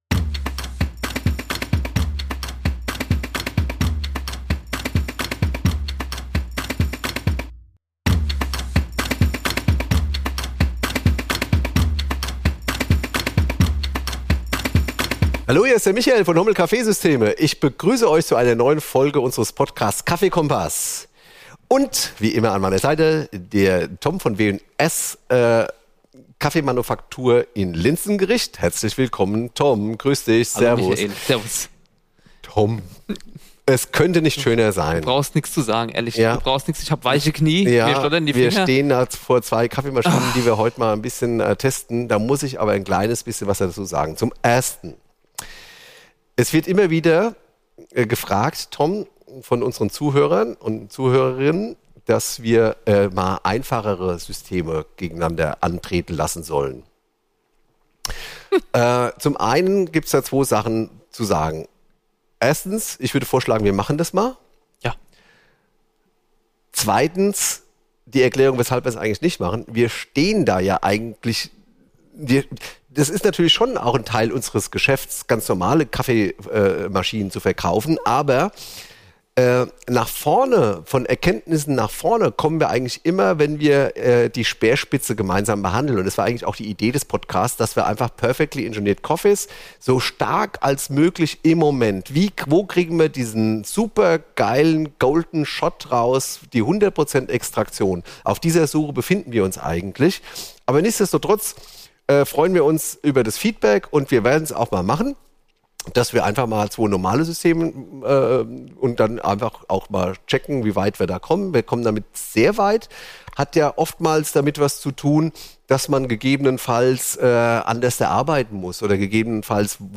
Live Test | La Marzocco Leva vs. La Marzocco Strada X | KaffeeKOMPASS überprüft | Folge 70 ~ KaffeeKOMPASS Podcast